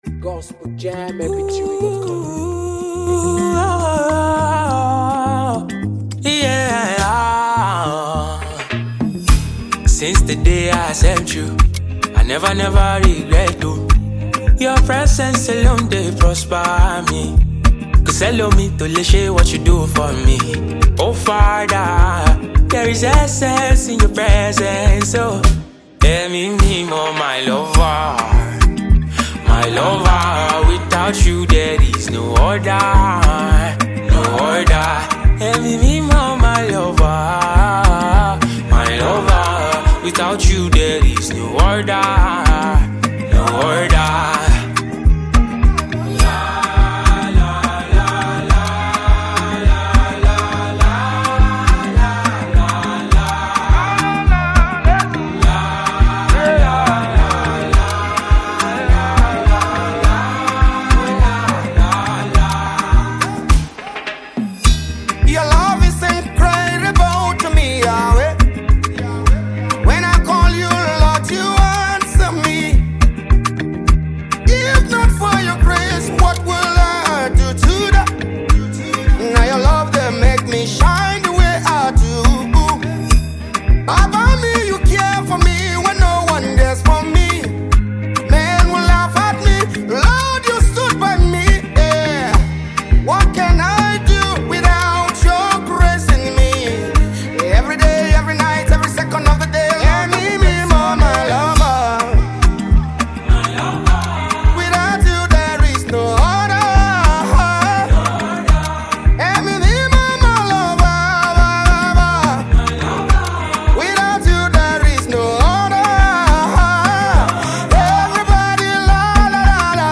an inspirational song